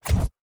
Tab Select 11.wav